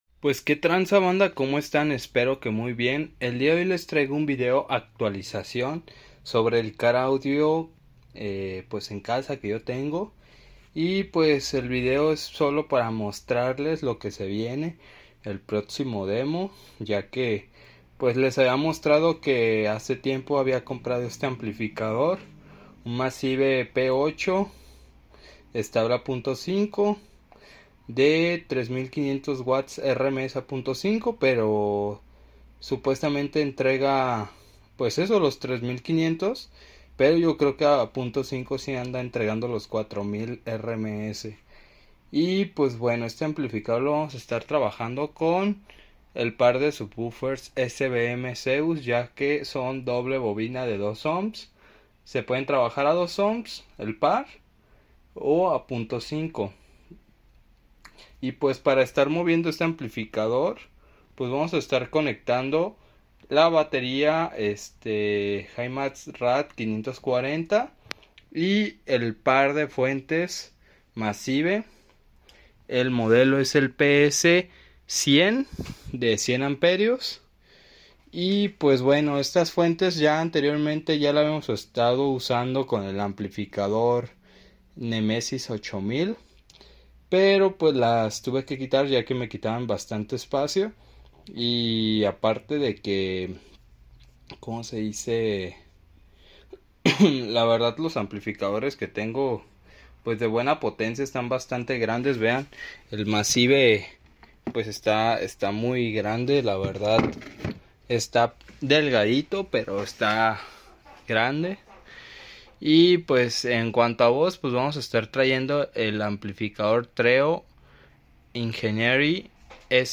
próximos demos Card audio en casa con amplificador Massive p8 4mil rms